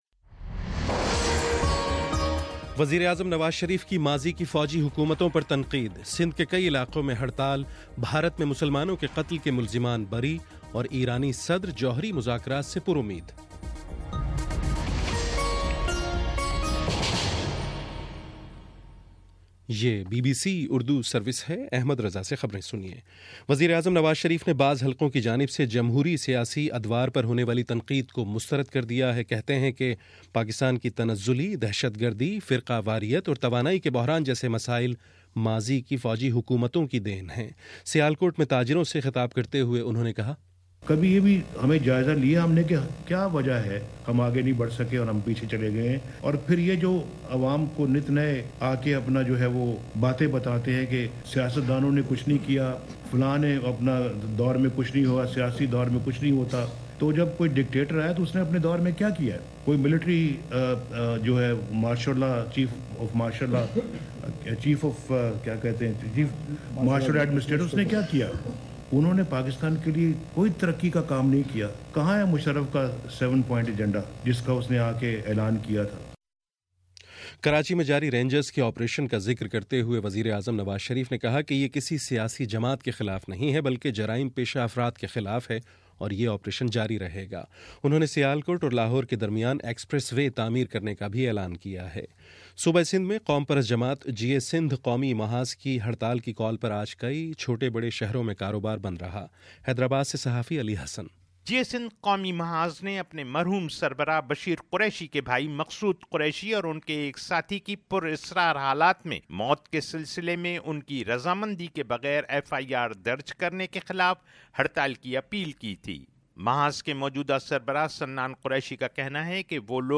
مارچ 21: شام چھ بجے کا نیوز بُلیٹن